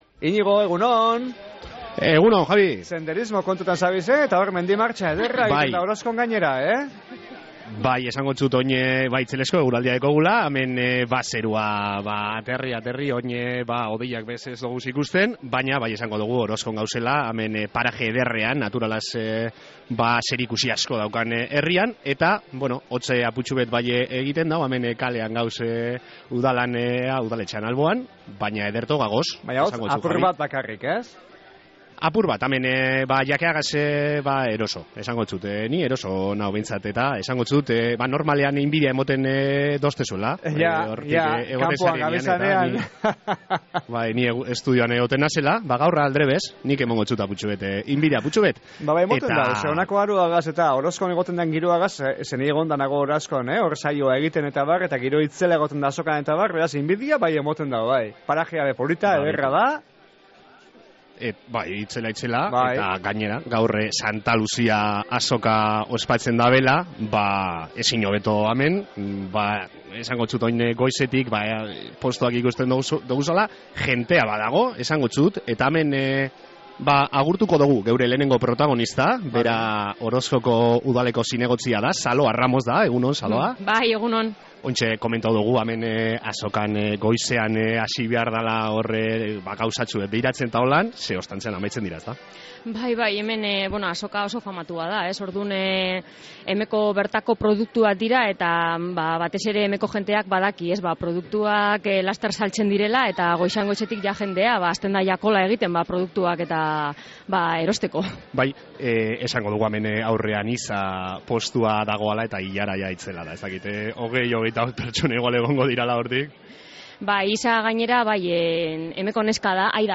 Zuzenean egin dogu irratsaioa Orozkoko Santa Luzia azokatik